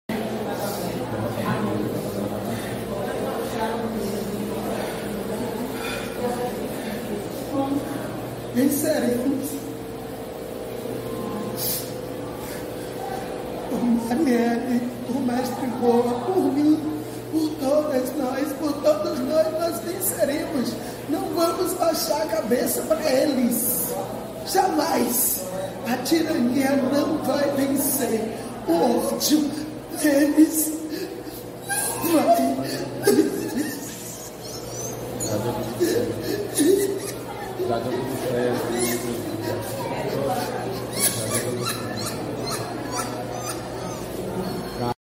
Olívia Santana aparece chorando após bate-boca com PM